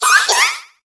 Cri de Larméléon dans Pokémon HOME.